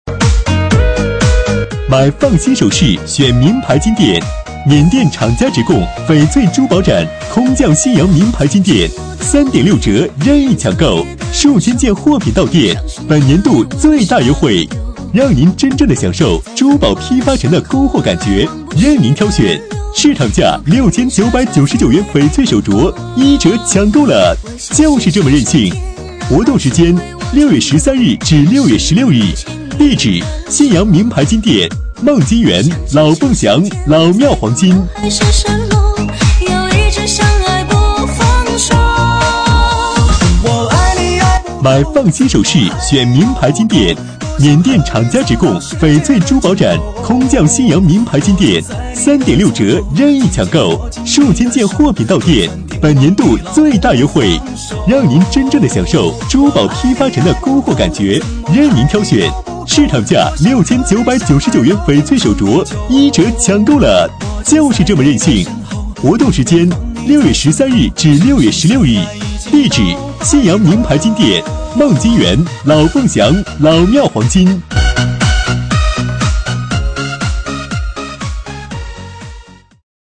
【男31号促销】名牌金店
【男31号促销】名牌金店.mp3